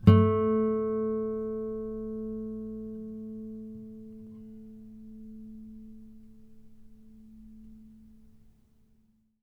harmonic-02.wav